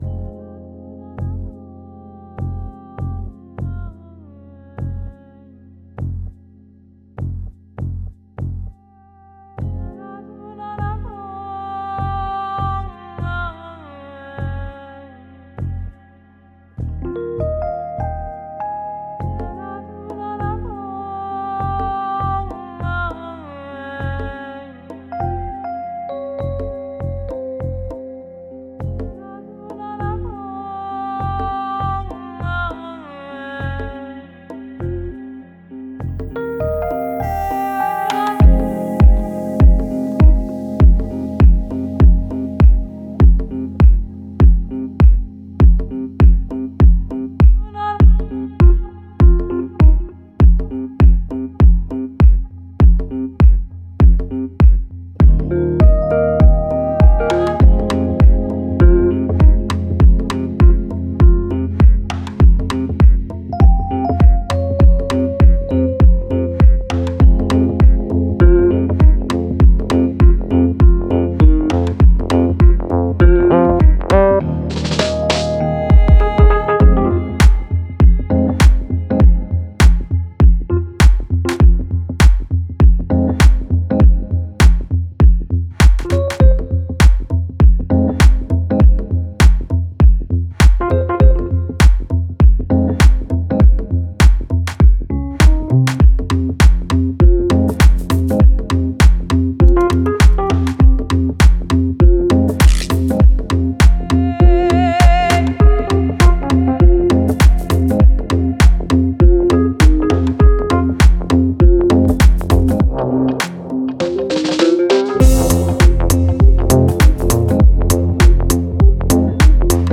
[Downtempo] Master gesucht für den Rabenkind-Remix...
Mir fehlt da Atmo, die Percussion Claps usw sind zu Vordergründig es fehlt mir an Diffusen zb dichten Hallfahnen, Rauschen sowas, Delay was sich fein dezent bemerkbar macht (gefiltert) da gibt es nichts zu entdecken das macht solche Musik für mich dann Belanglos.
hier mein erster versuch mit dem neuen mix.